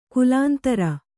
♪ kulāntara